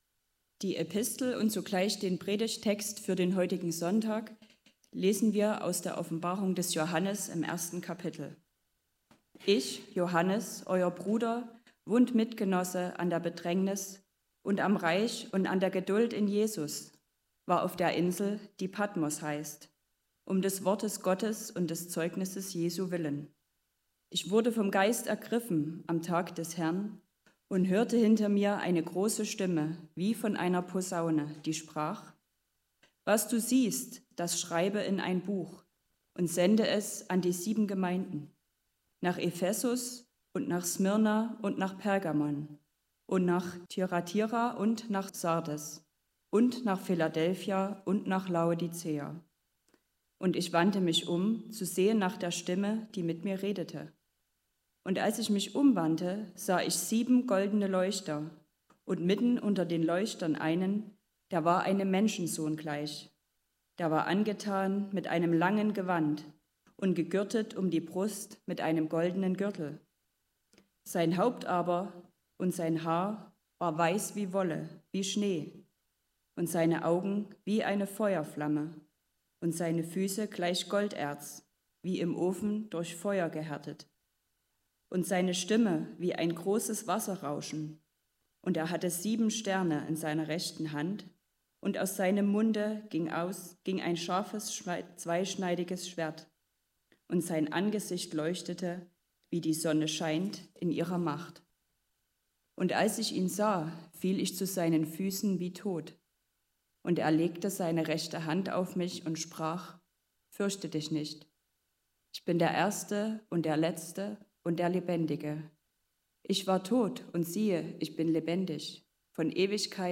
9-18 Gottesdienstart: Predigtgottesdienst Obercrinitz Ich höre